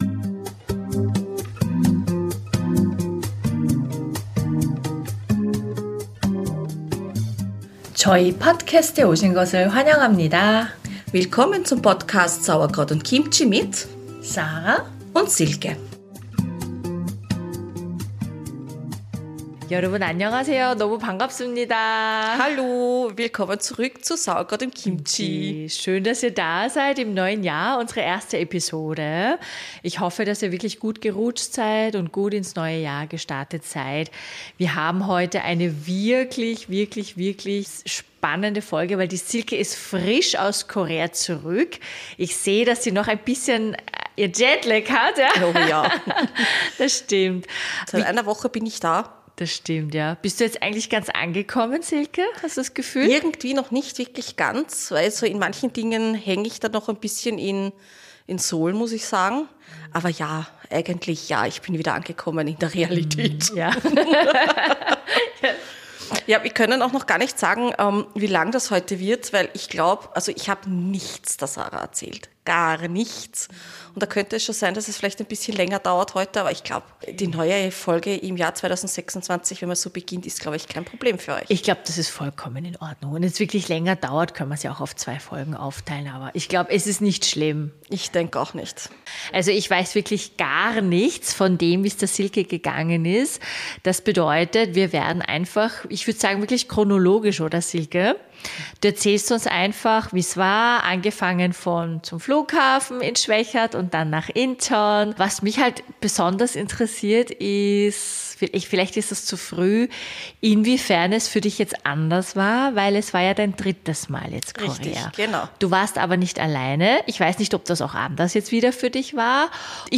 Wir plaudern darüber, wie es sich anfühlt zum dritten Mal dort zu sein? Was ändert sich, was ist neu und welche neuen Tipps gibt es vielleicht. Die Folge ist ein wenig länger und lädt einfach zum Zuhören und Mitlachen ein.